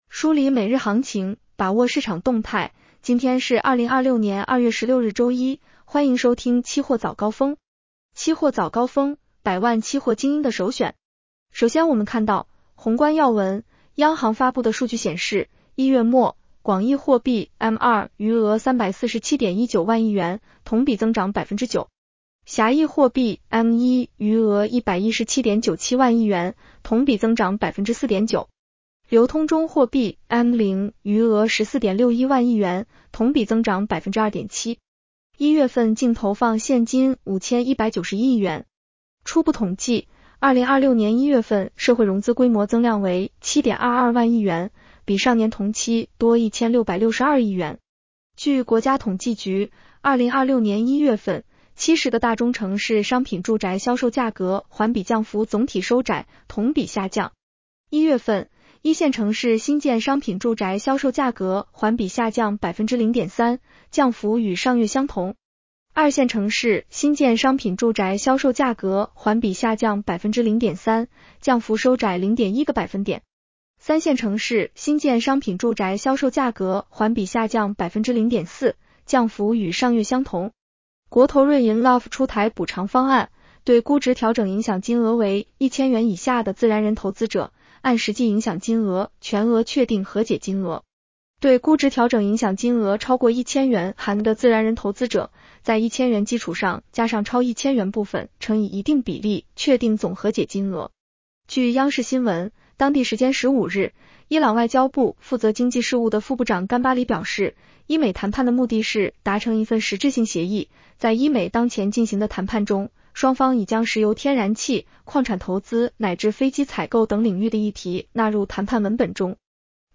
期货早高峰-音频版 女声普通话版 下载mp3 热点导读 1.